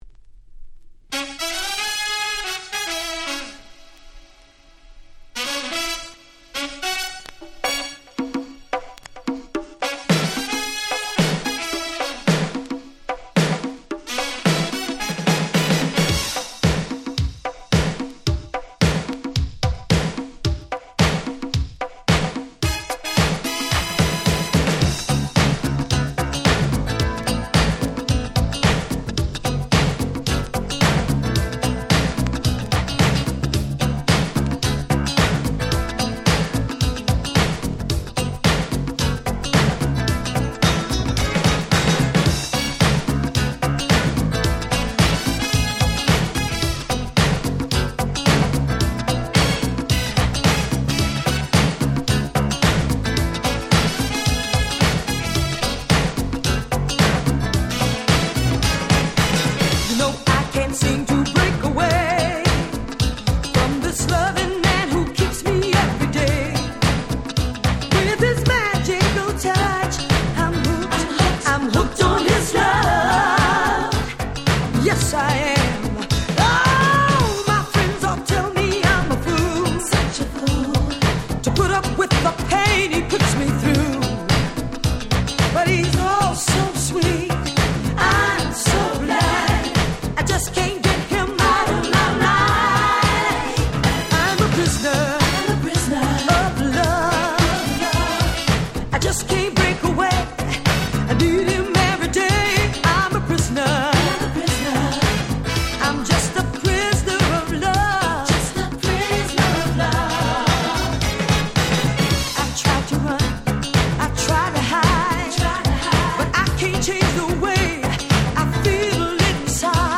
87' Nice Dance Classics / Disco コンピレーション！！